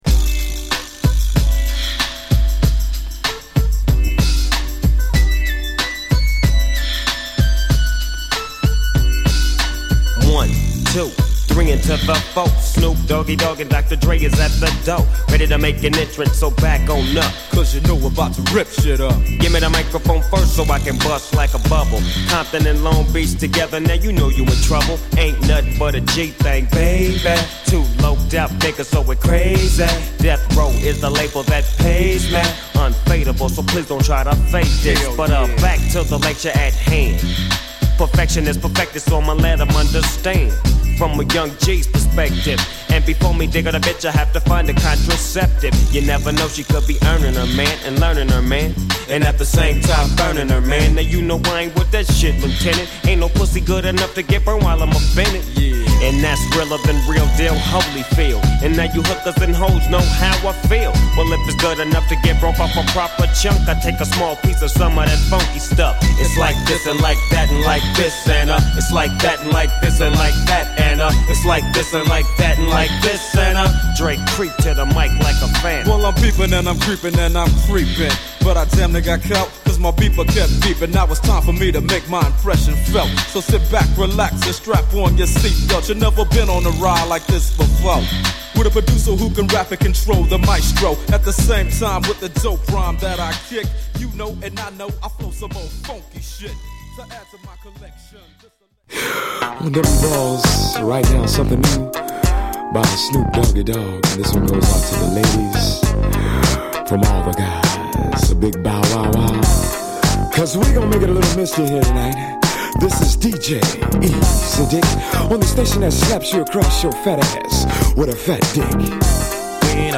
オフィシャルって事で2LPにてリリース、音質/音圧もバッチリです。